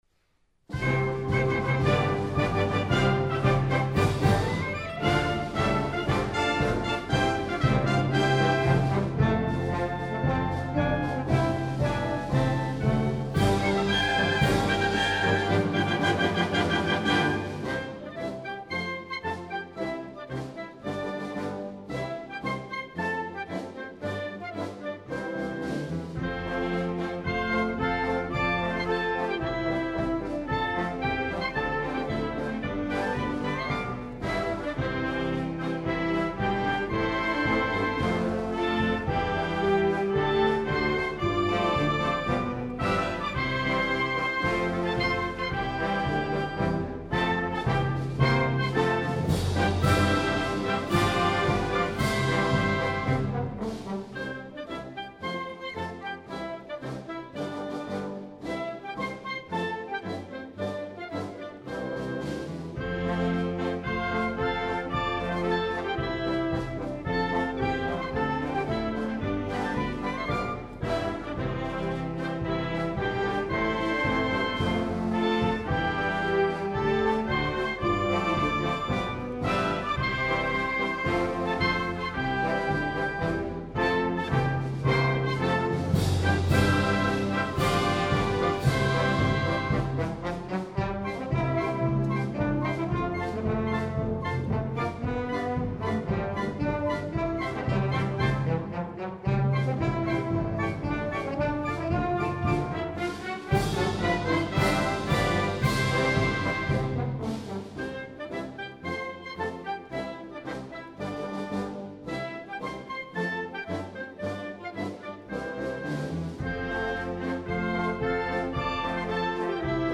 ■ 校歌（演奏）